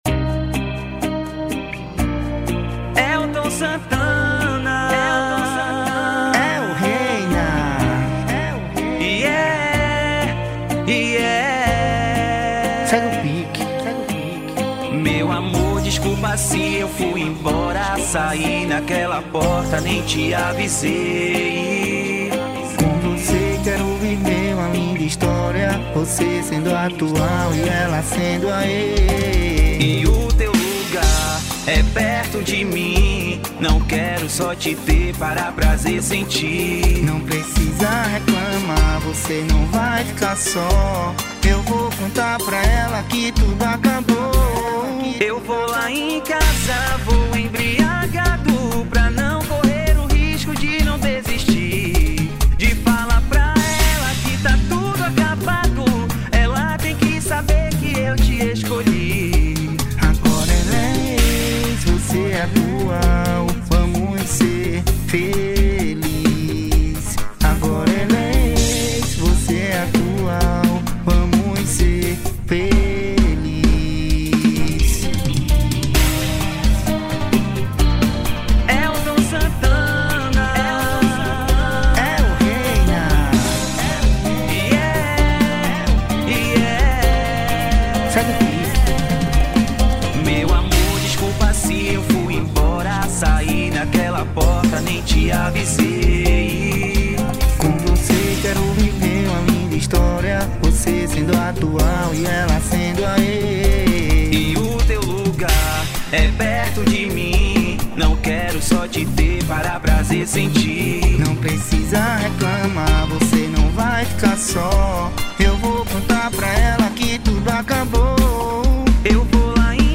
EstiloBrega Funk